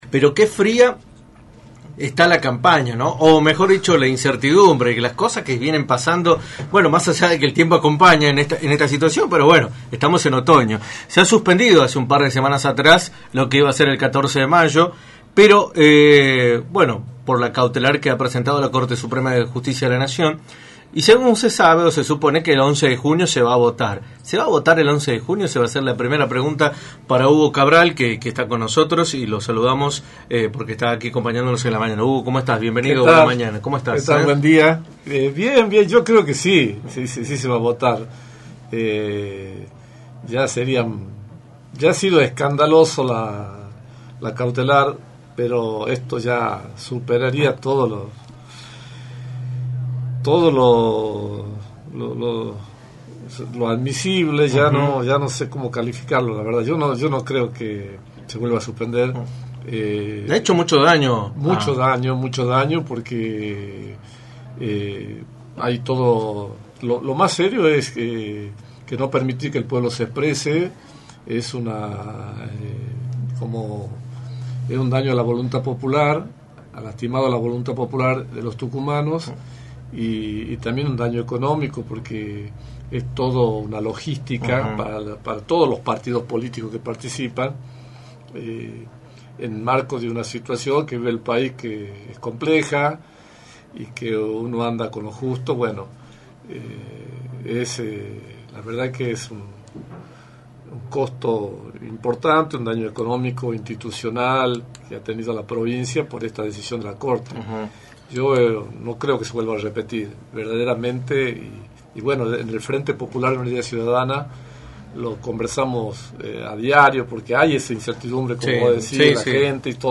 visitó los estudios de Radio del Plata Tucumán, por la 93.9